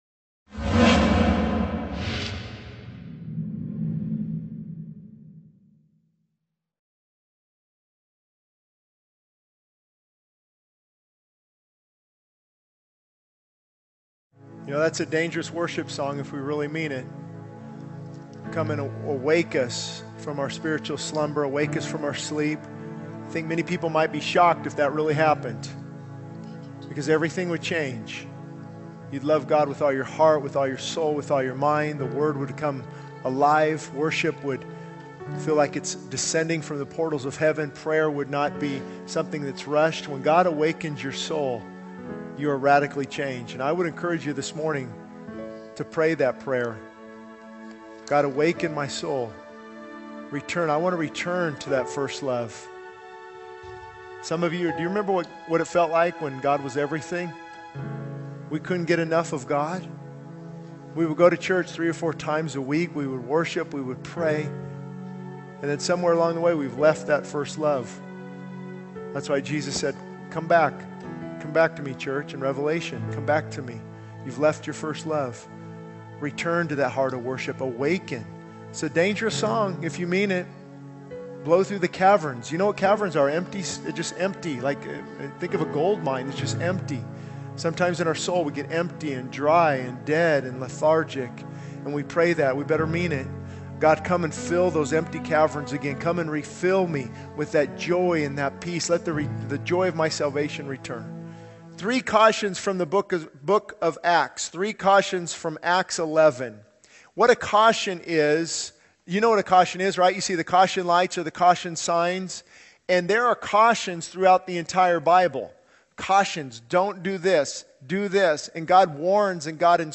This sermon emphasizes the importance of awakening our spiritual lives from slumber, returning to our first love for God, and rekindling a heart of worship. It challenges listeners to pray for a soul awakening and to seek a deeper relationship with God. The sermon also highlights the need for genuine compassion, giving from the heart, and serving others as a reflection of God's love.